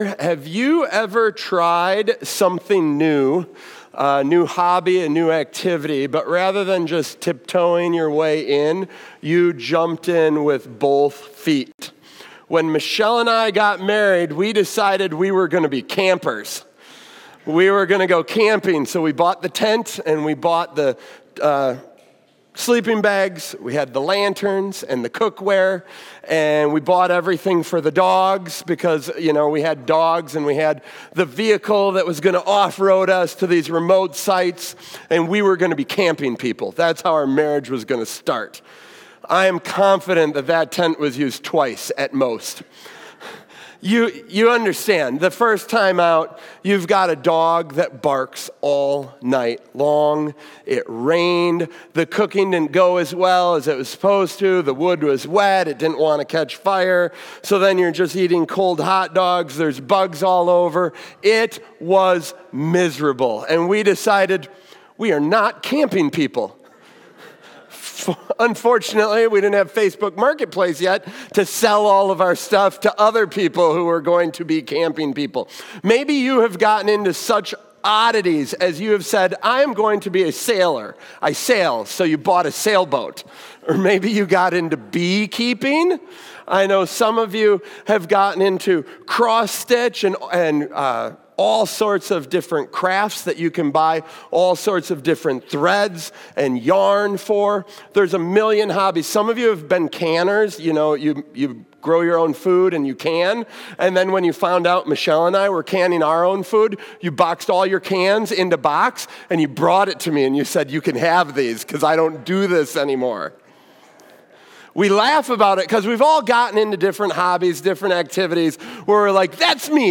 In this final message of our “All In” series, we explore why faith isn’t just a moment but a lifetime journey of mountains and valleys.